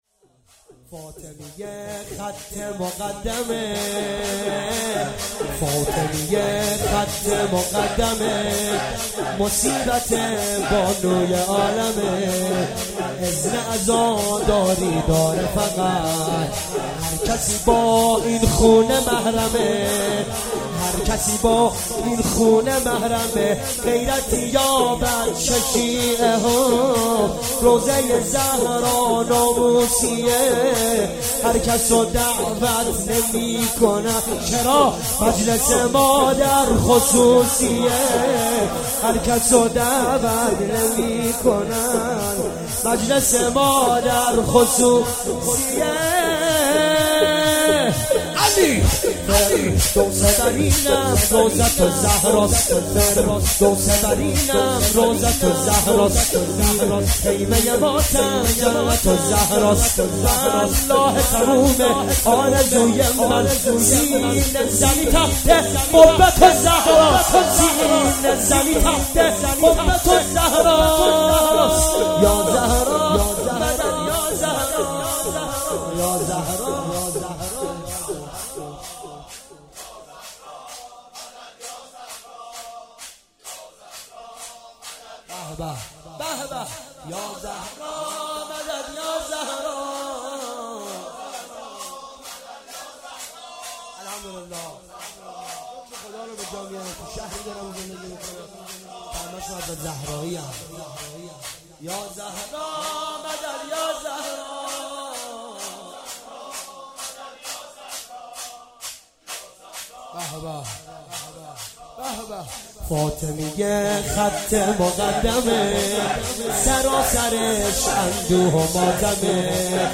شور- فاطمیه خط مقدمه